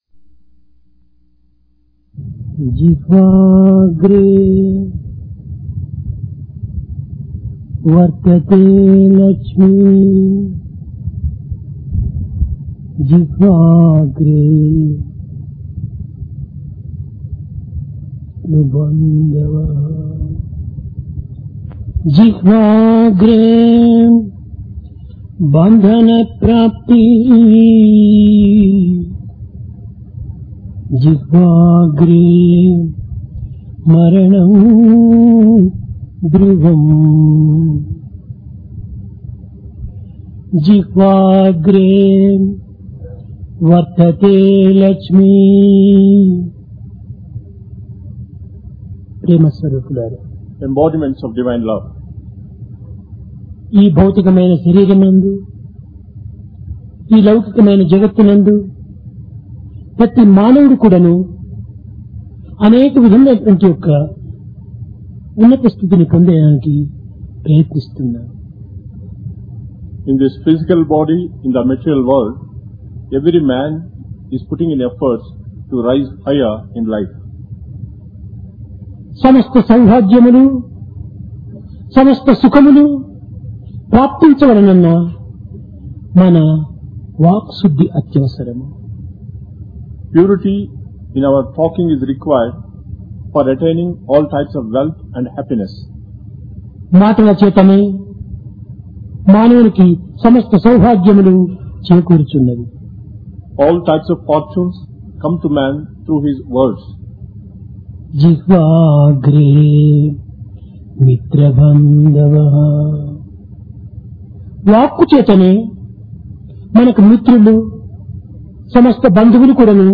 Discourse